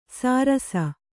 ♪ sārasa